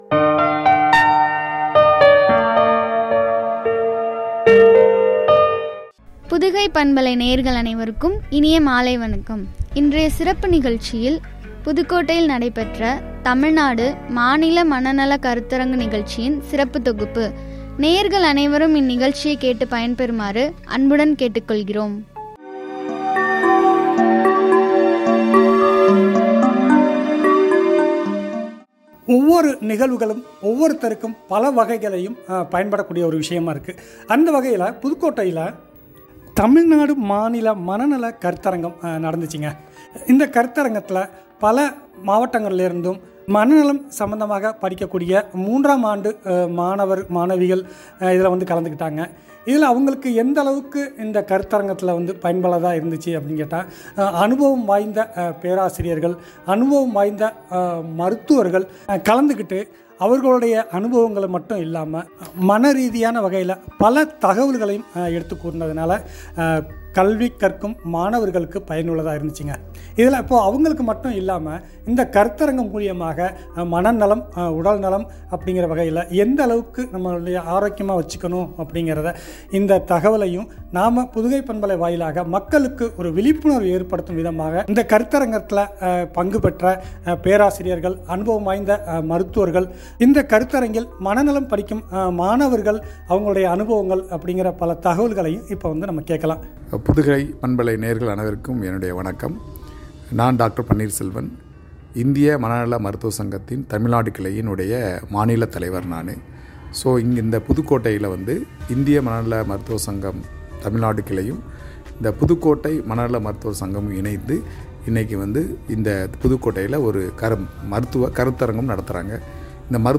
புதுக்கோட்டை , தமிழ்நாடு மாநில மனநல கருத்தரங்கு நிகழ்ச்சியின் சிறப்பு தொகுப்பு உரையாடல்.